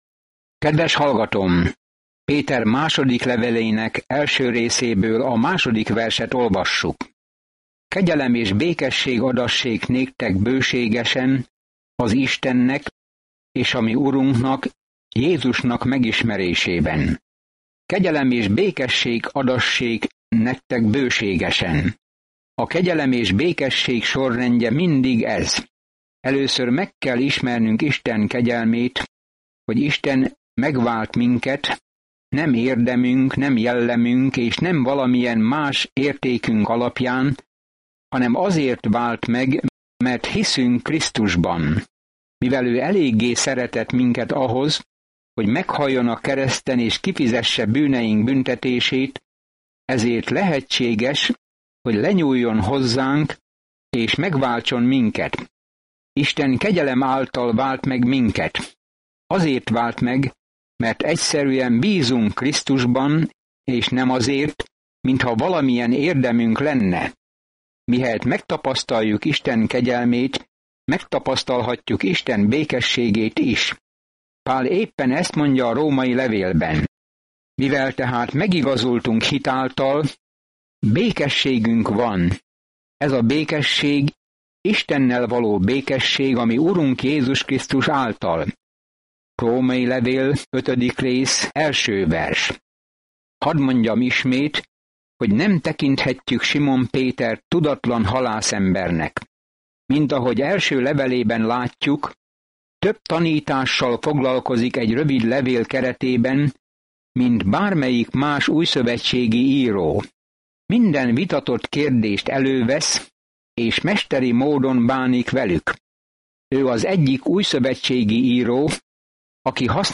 Szentírás 2Péter 1:2-4 Nap 1 Olvasóterv elkezdése Nap 3 A tervről Péter második levele Isten kegyelméről szól – hogyan mentett meg minket, hogyan tart meg minket, és hogyan élhetünk benne – annak ellenére, amit a hamis tanítók mondanak. Napi utazás Péter 2-án, miközben hallgatja a hangos tanulmányt, és olvassa el Isten szavának kiválasztott verseit.